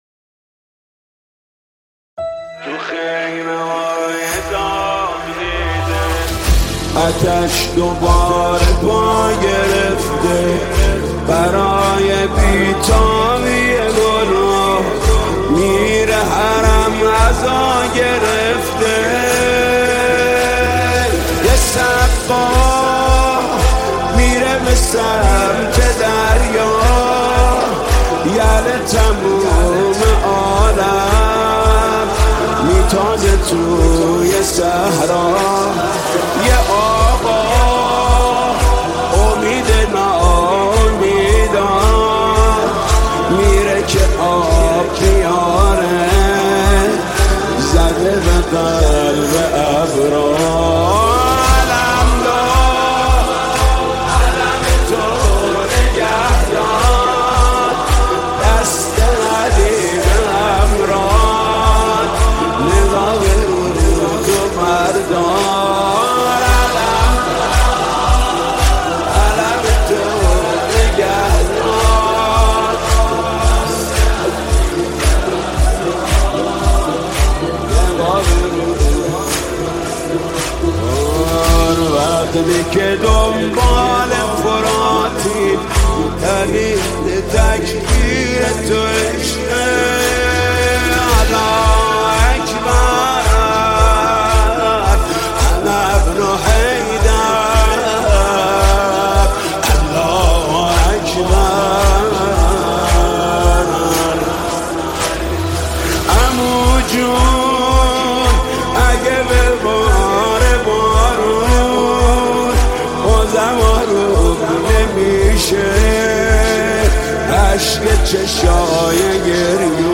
نماهنگ دلنشین
ویژه تاسوعای حسینی